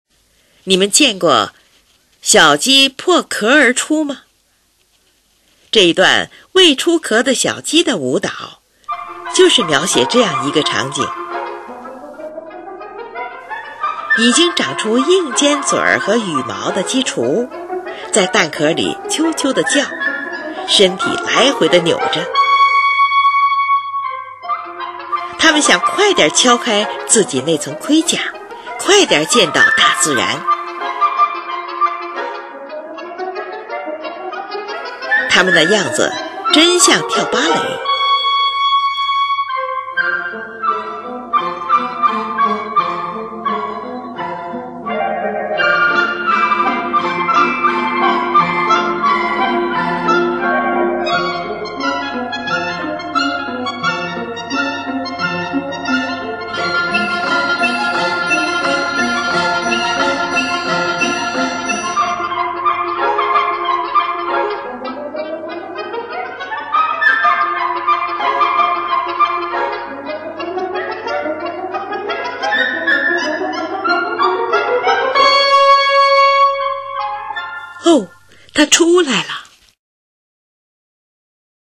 他把整段乐曲放在高音区中进行，用以强调表现出那种尖声尖气的音响。
乐曲当中一段在小提琴上大量明亮的颤音，也使音乐添加一种幽默的色彩。
这个来说，这是一种小诙谐曲，像是一种古典芭蕾舞。